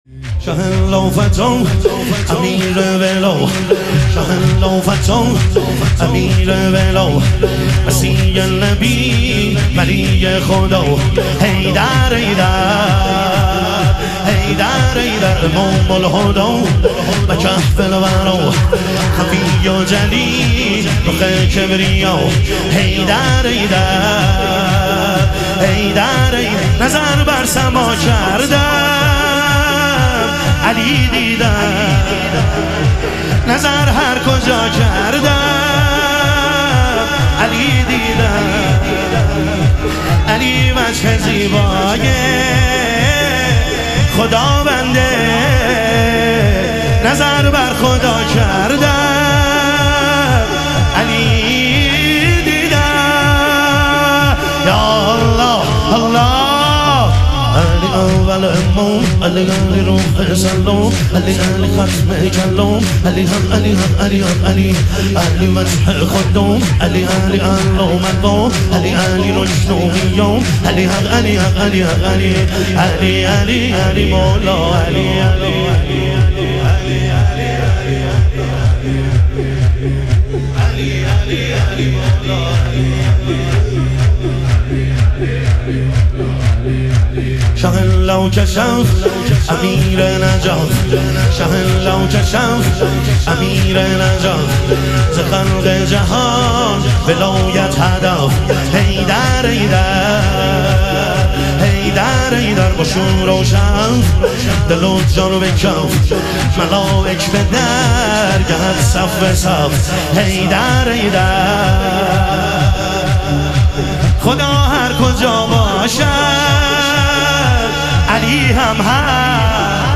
شور
شب اربعین امیرالمومنین علیه السلام